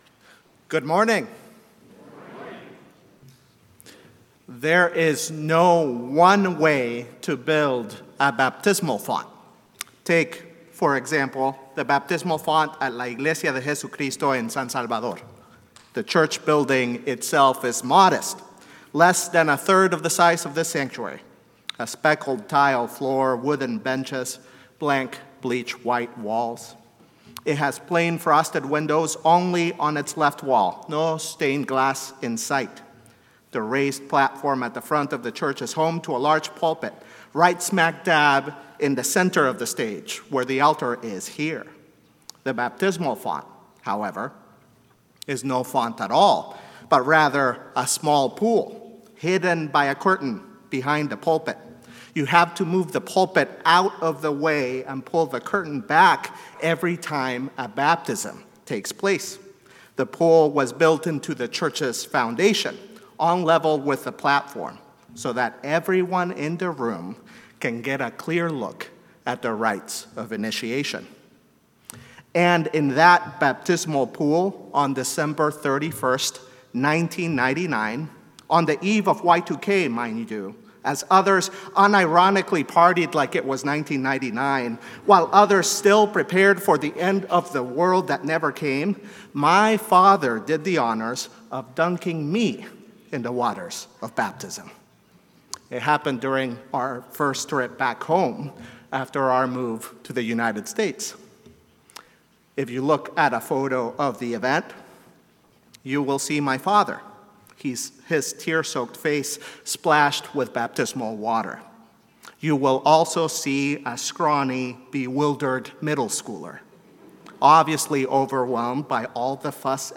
St-Pauls-HEII-9a-Homily-08SEP24.mp3